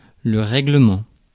Ääntäminen
IPA: /ʁɛ.ɡlǝ.mɑ̃/